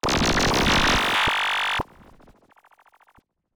Glitch FX 20.wav